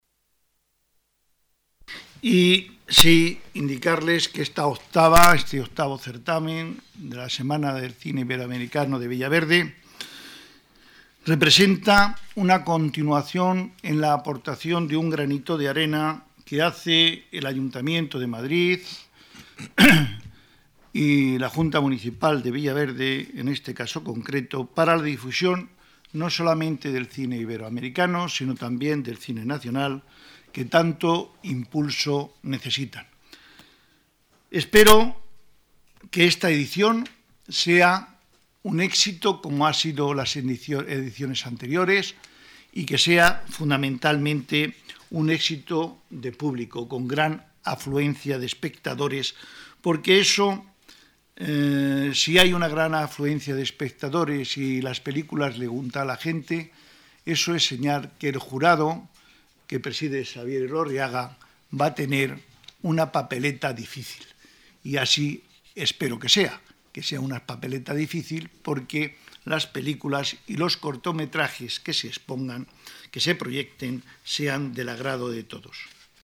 Nueva ventana:Declaraciones concejal Villaverde, Joaquín Martínez: festival cine La Chimenea